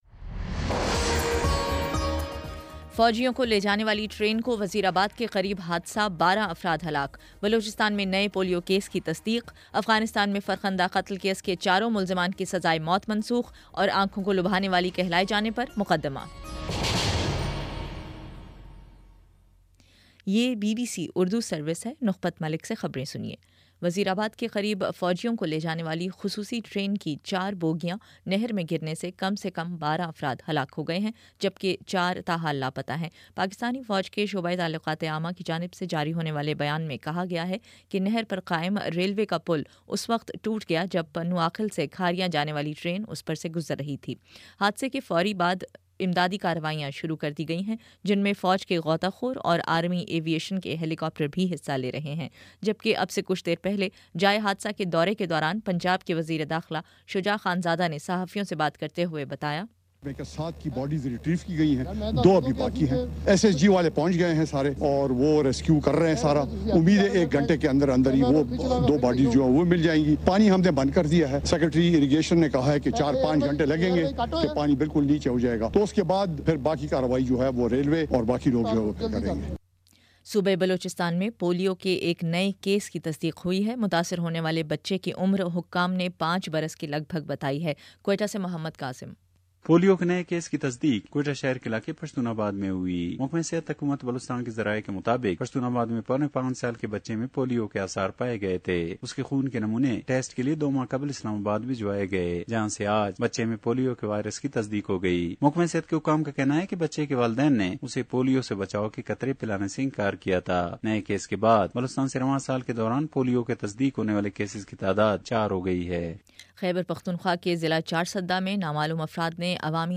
جولائی 2: شام پانچ بجے کا نیوز بُلیٹن